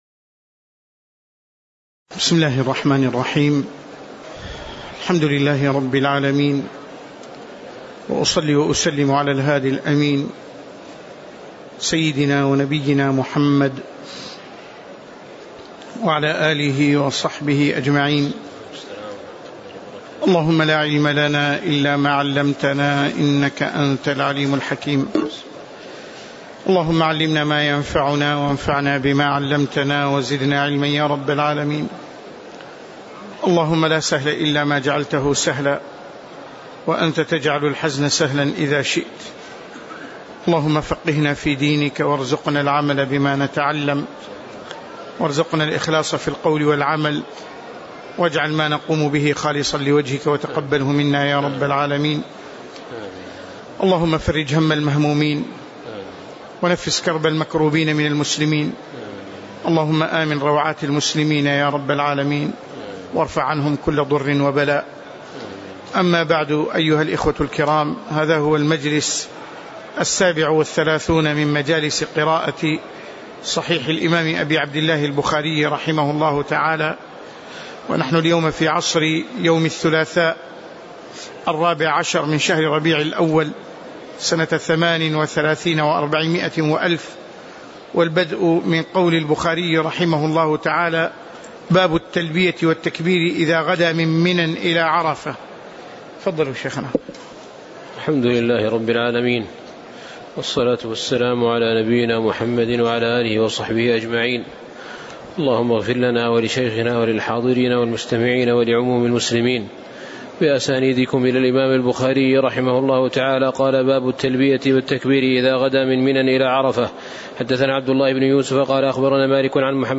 تاريخ النشر ١٤ ربيع الأول ١٤٣٨ هـ المكان: المسجد النبوي الشيخ